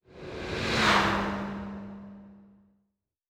Fly By 03_3.wav